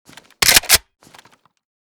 ak12_unjam.ogg